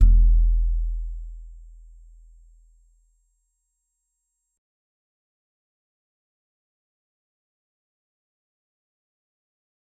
G_Musicbox-E1-mf.wav